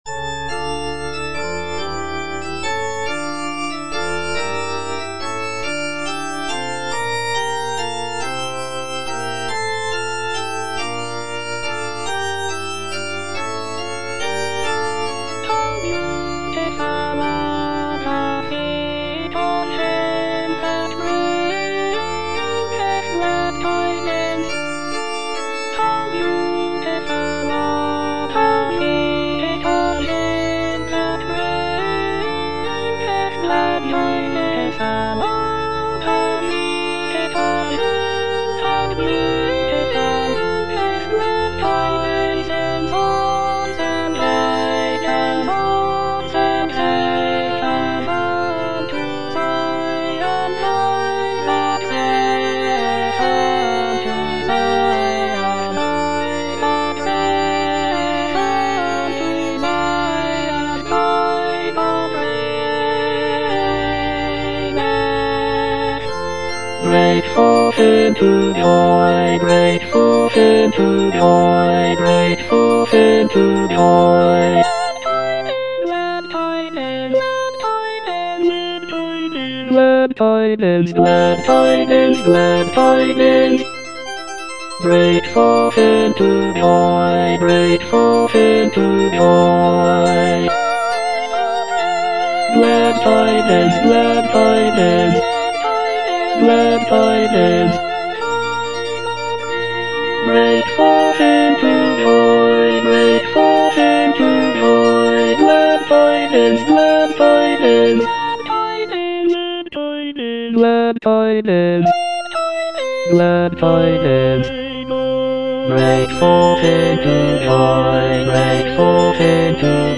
G.F. HÄNDEL - HOW BEAUTIFUL ARE THE FEET OF HIM FROM "MESSIAH" (DUBLIN 1742 VERSION) Bass (Emphasised voice and other voices) Ads stop: